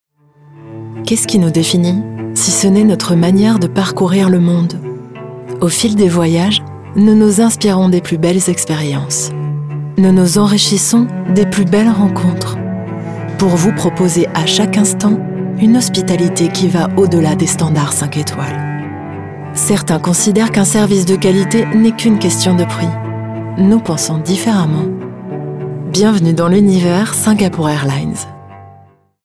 Bandes-son
Voix off
7 - 53 ans - Contralto
Accent Belge